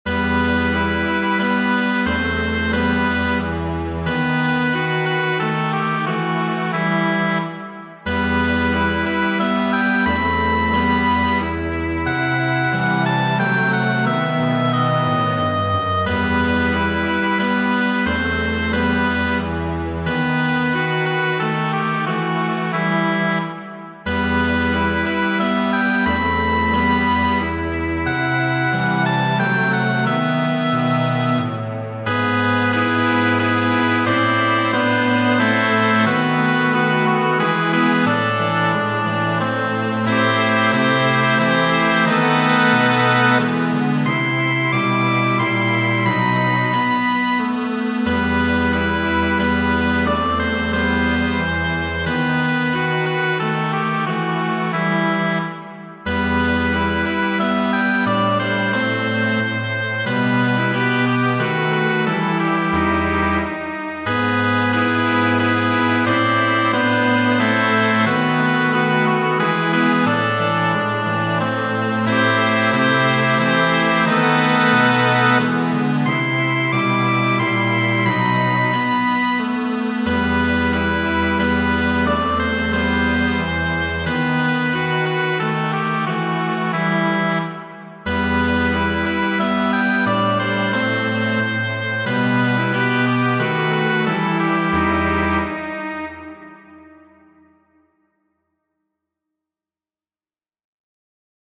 Muziko :
Replendo estas adapto de Studo de Fernando Sor, kiu memorigas la trankvilecon, kiu mi sentis, kiam mi faris la staĝon de Séte en 2000.